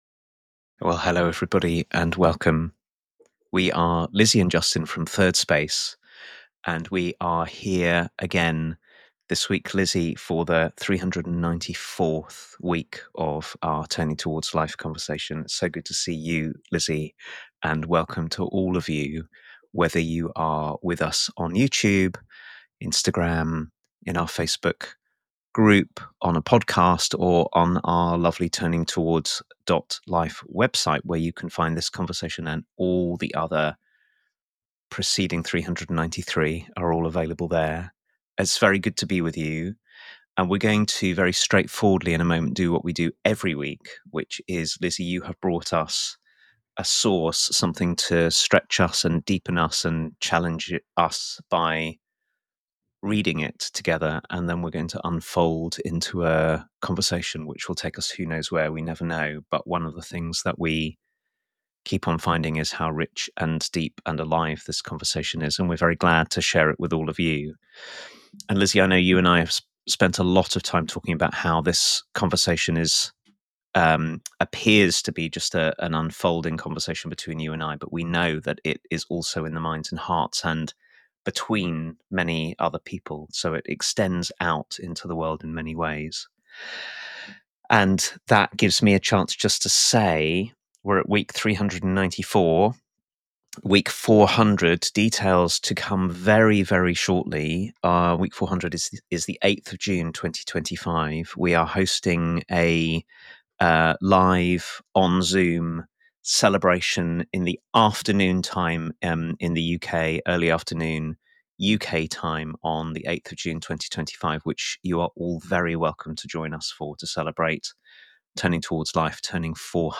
In this conversation, we explore a poem by Wendell Berry which calls us to wake up from the numbing effects of conventional life and practice living with true aliveness.